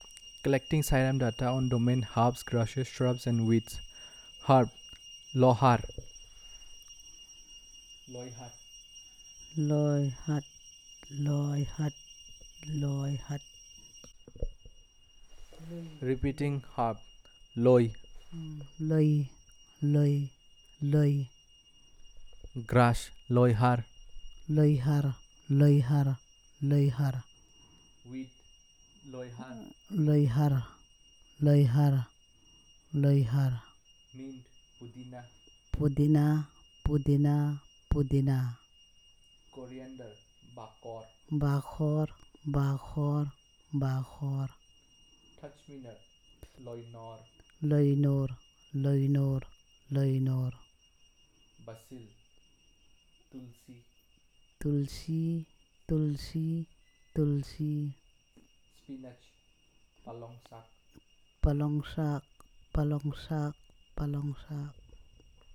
Elicitation of words about herbs, grasses, shrubs and weeds